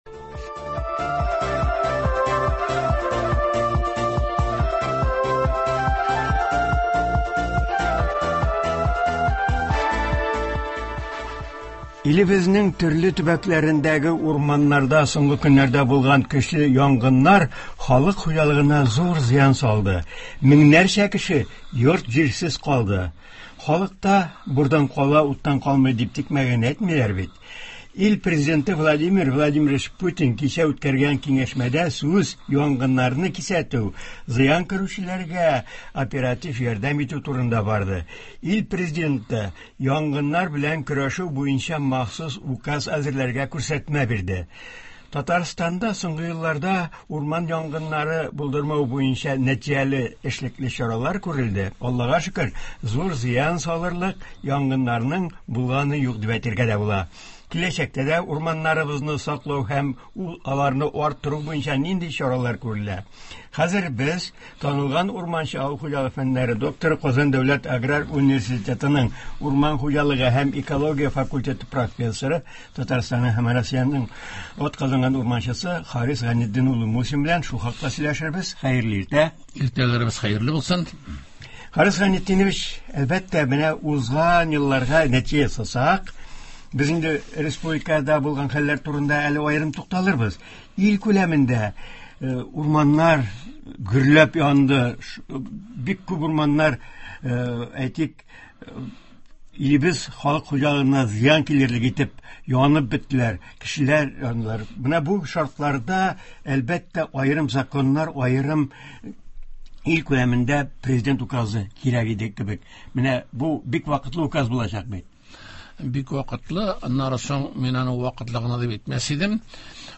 Туры эфир (11.05.22)
Яз — игенчеләр өчен чәчү чоры булса, урманчылар өчен агачлар утырту вакыты. Турыдан-туры элемтә тапшыруында